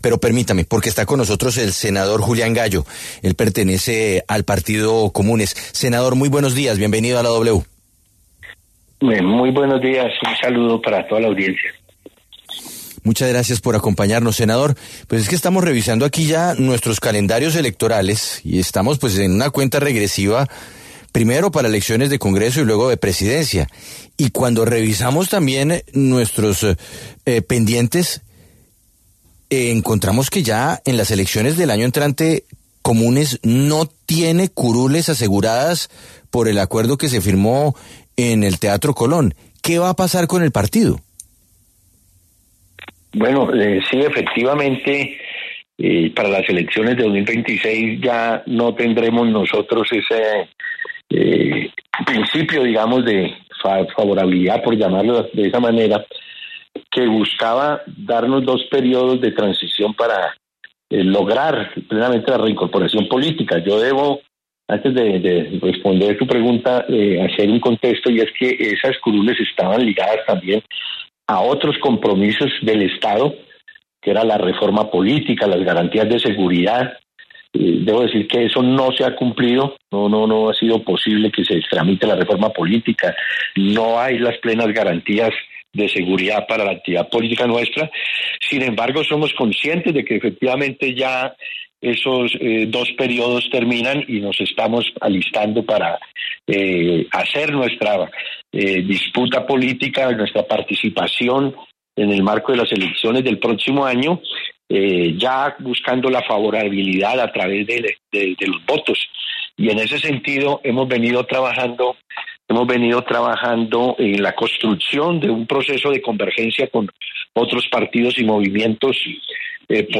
El senador Julián Gallo, del Partido Comunes, habló con La W sobre el futuro político de su colectividad, de cara a las elecciones de 2026, cuando pierden las 10 curules aseguradas, en Senado y Cámara, que les otorgó el Acuerdo de Paz.